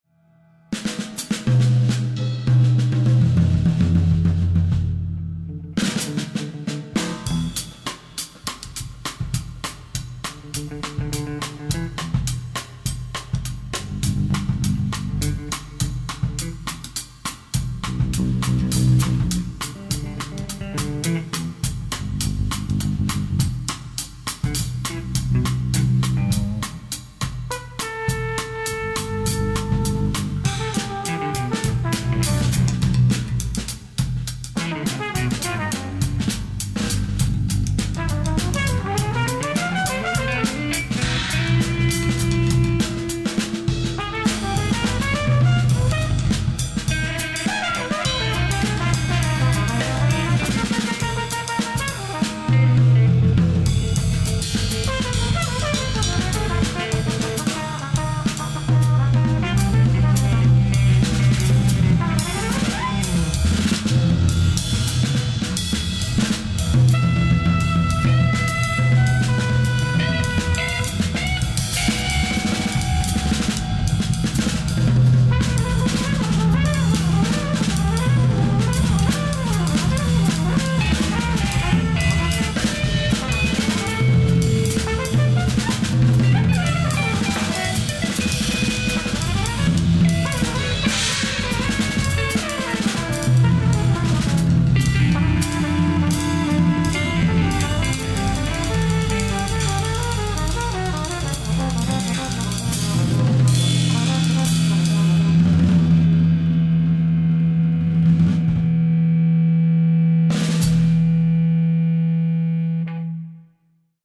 percussion
bass
guitar
trumpet/flugelhorn